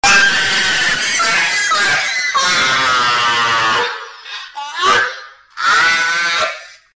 High Pitched Laughing